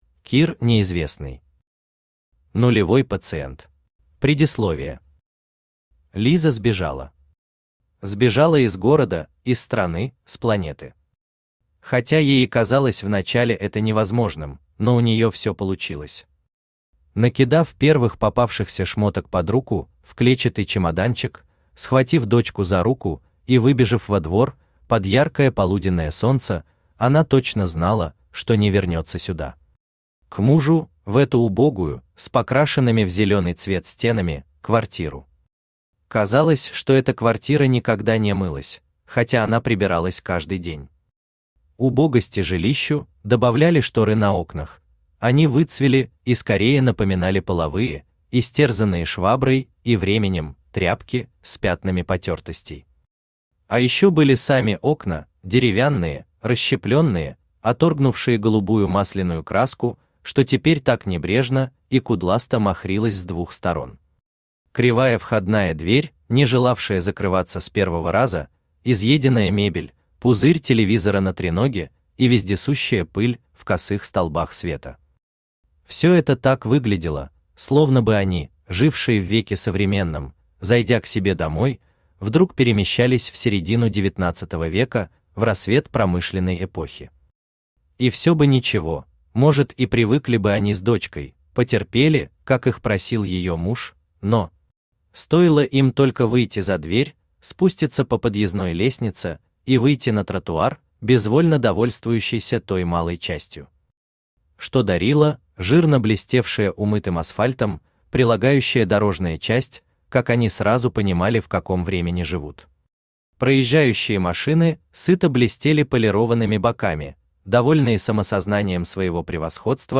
Аудиокнига Нулевой пациент | Библиотека аудиокниг
Aудиокнига Нулевой пациент Автор Кир Николаевич Неизвестный Читает аудиокнигу Авточтец ЛитРес.